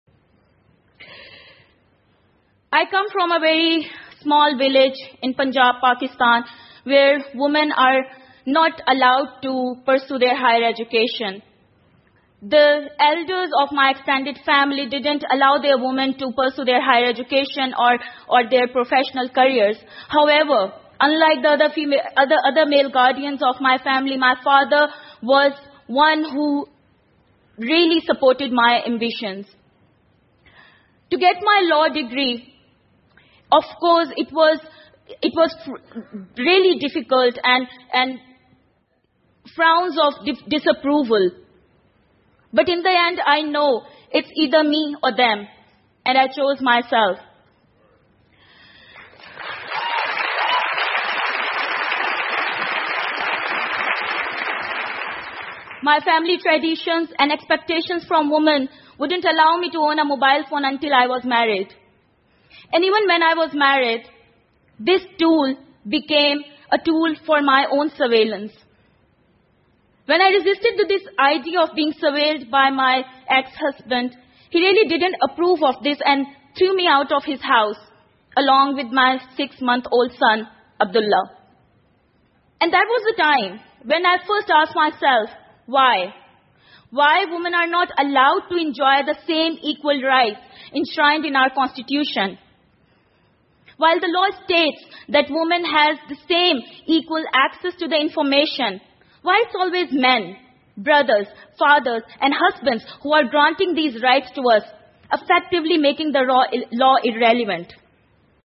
TED演讲:巴基斯坦女性如何夺回上网的权力() 听力文件下载—在线英语听力室